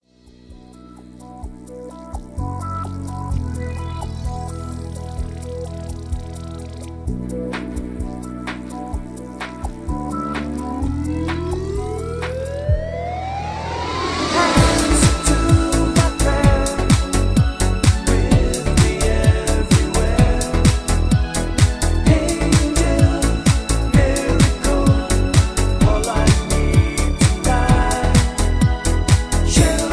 (Version-3, Key-A) Karaoke MP3 Backing Tracks
Just Plain & Simply "GREAT MUSIC" (No Lyrics).